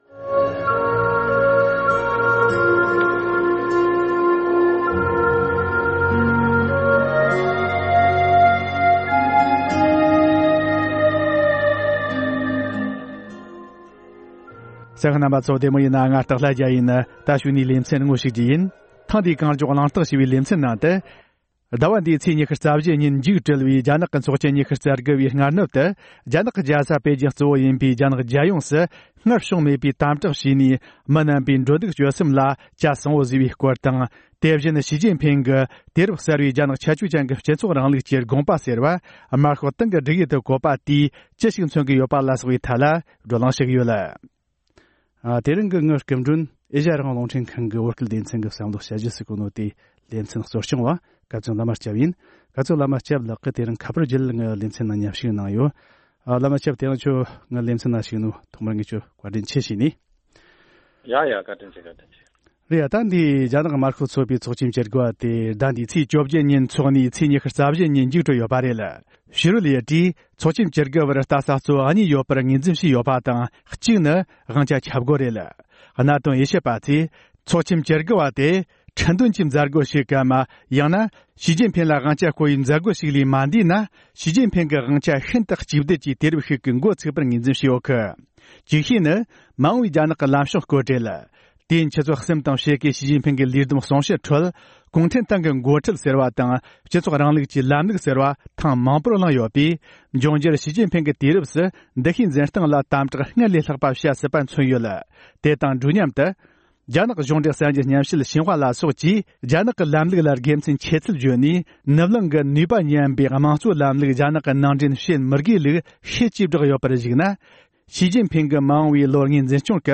ཞི་ཅིན་ཕིང་ལ་བླ་ན་མེད་པའི་དབང་ཆ་ཐོབ་པར་བོད་དོན་སེལ་རྒྱུར་དགེ་མཚན་ཡོད་མེད་ལ་སོགས་པའི་ཐད་གླེང་མོལ་གནང་བ།